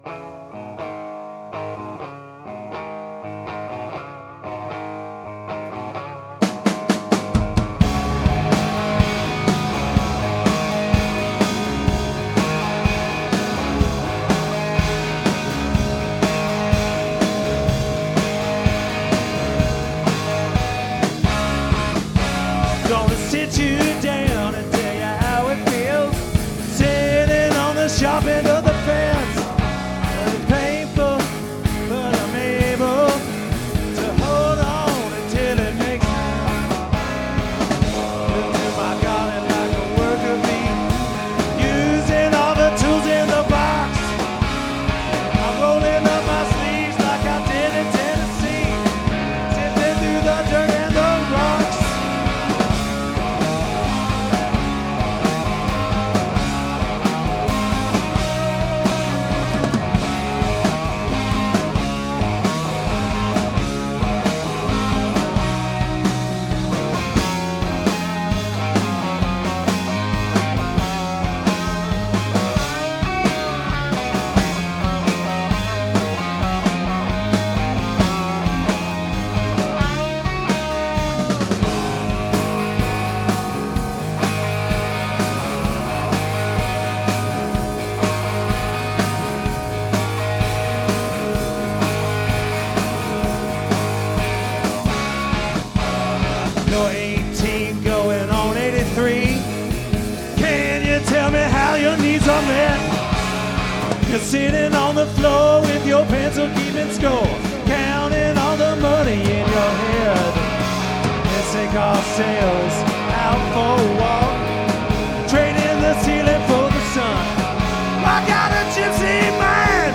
2007-04-23 Neumo’s – Seattle, WA